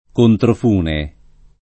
controfune [ kontrof 2 ne ]